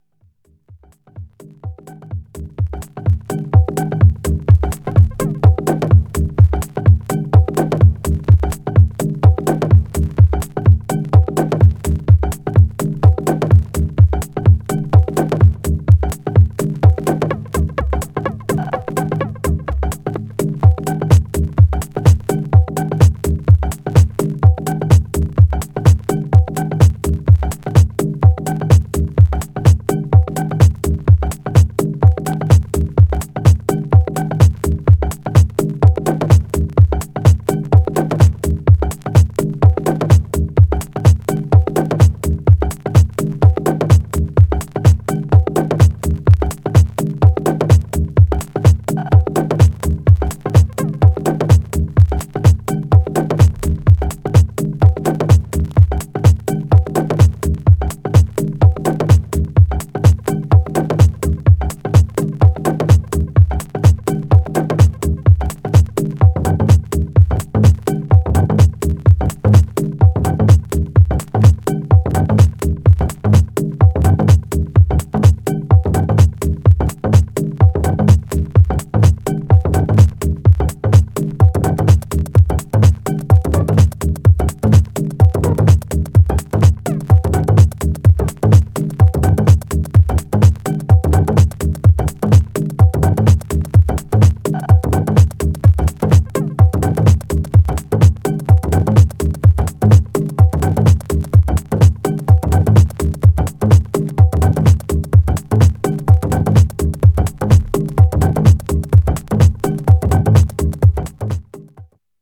Styl: House, Techno, Minimal